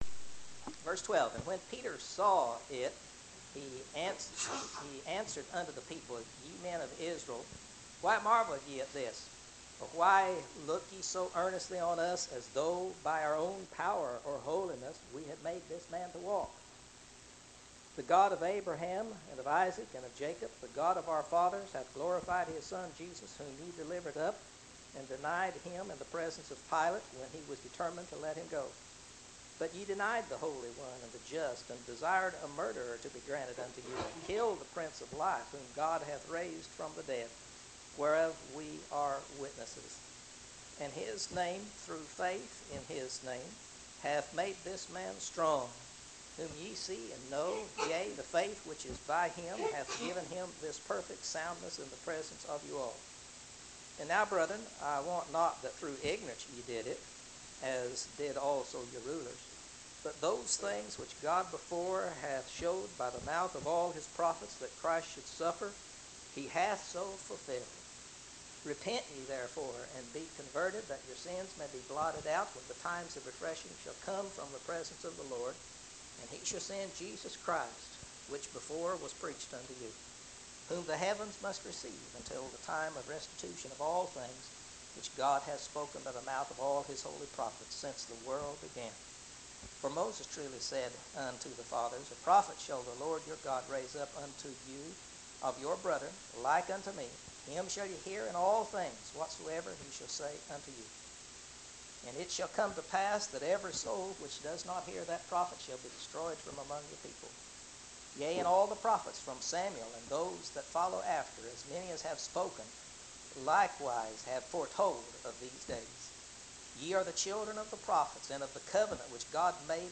Bible Class – Acts